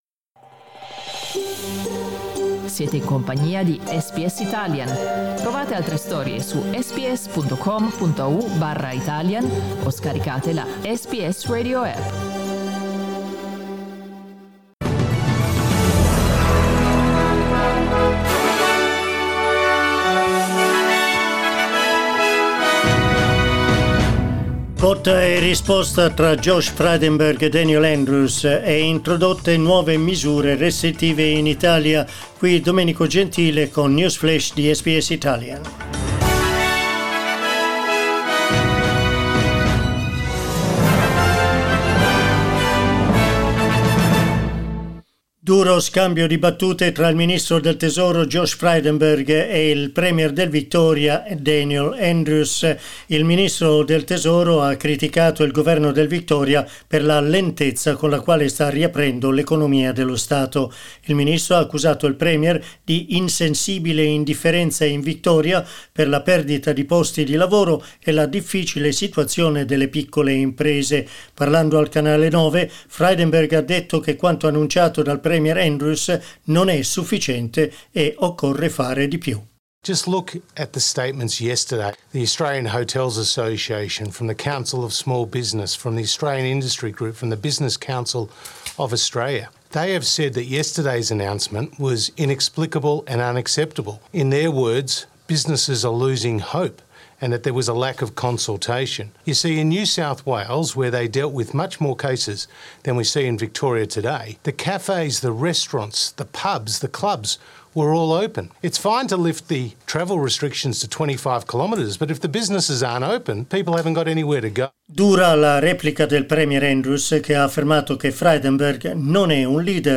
L'aggiornamento del GR di SBS Italian.